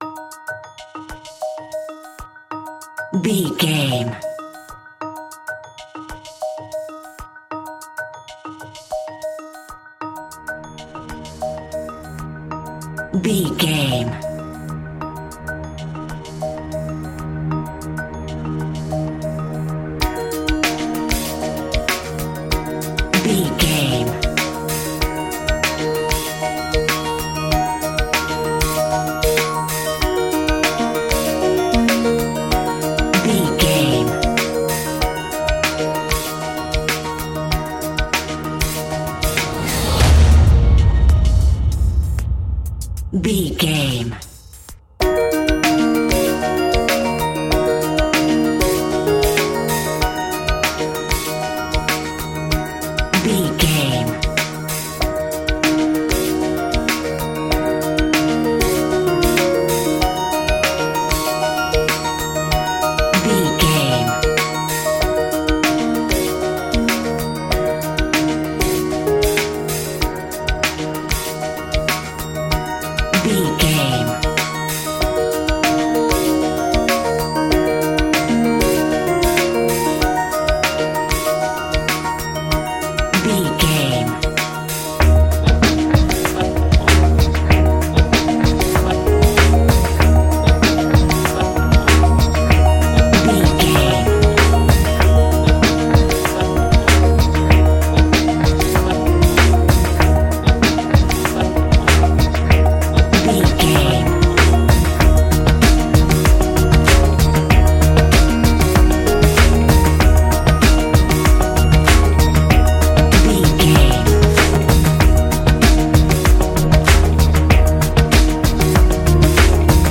Ionian/Major
D♯
electronic
techno
trance
synths
synthwave